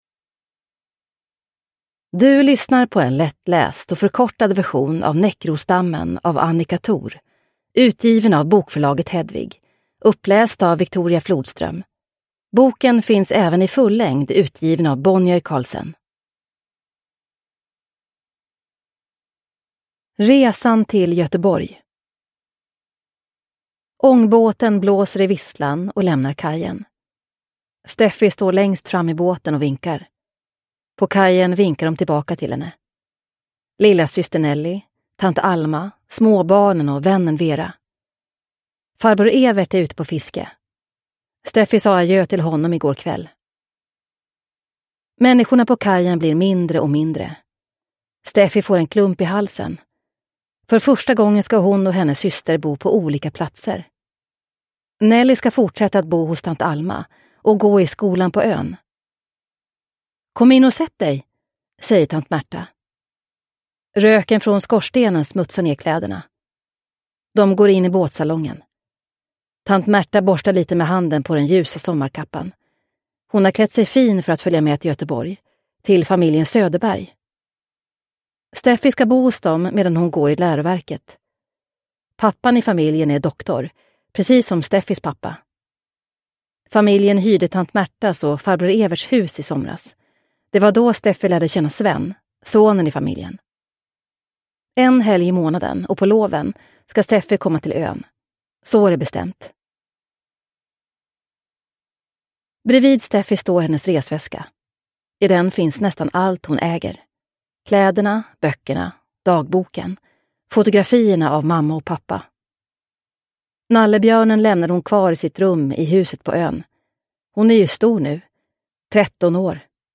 Näckrosdammen (lättläst) – Ljudbok